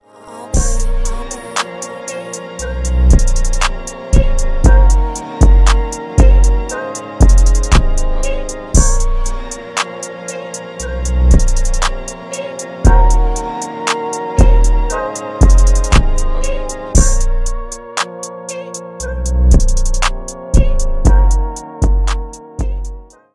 Рэп и Хип Хоп
без слов